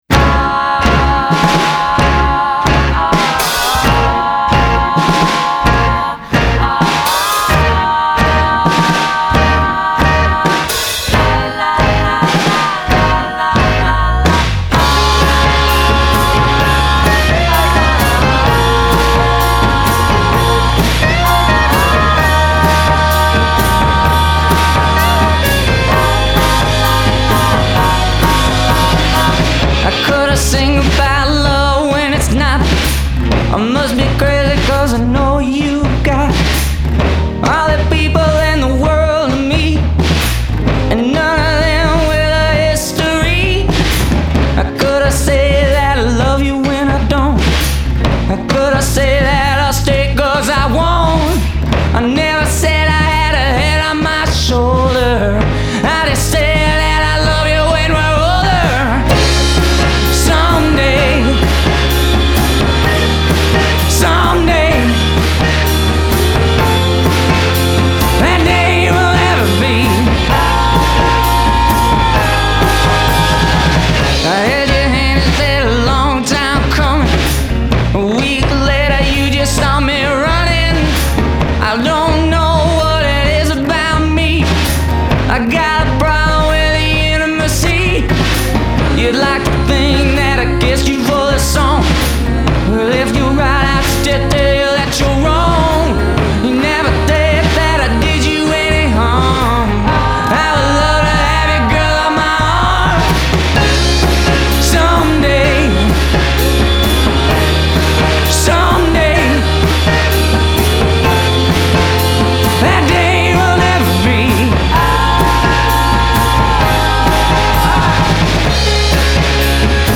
featuring a 50’s melody.